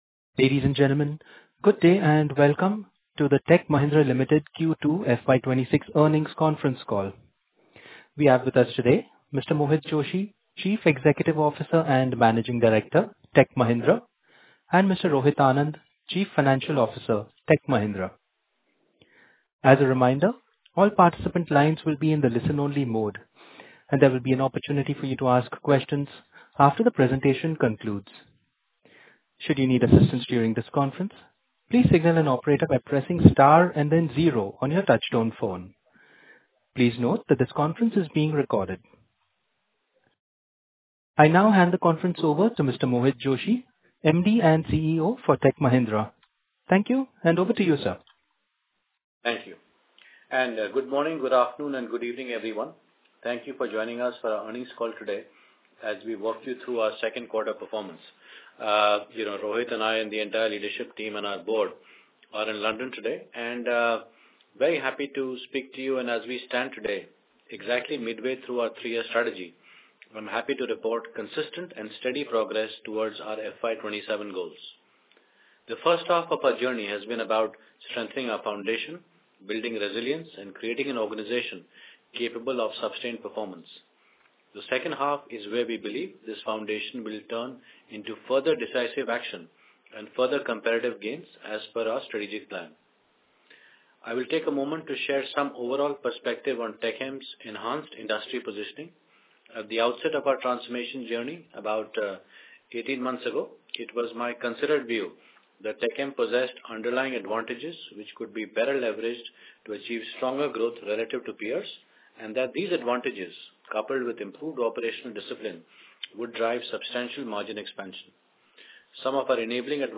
earnings-call-q2fy26.mp3